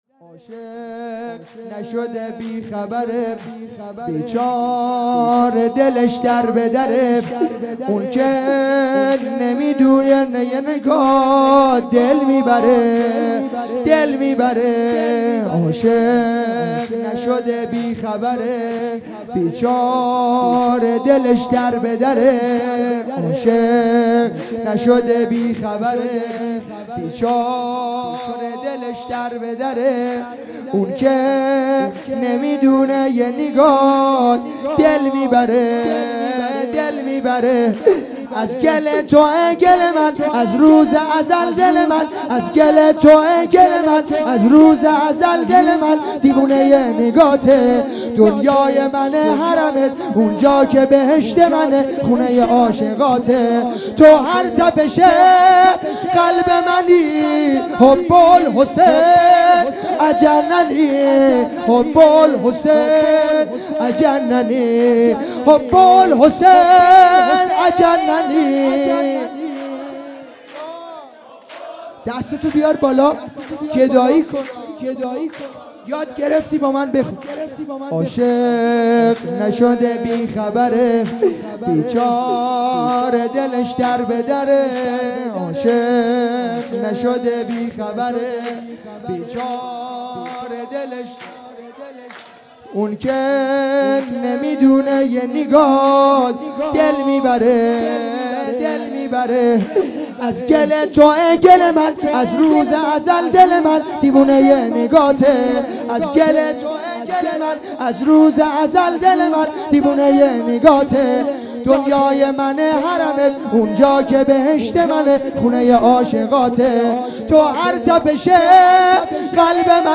جشن ولادت امام حسین علیه السلام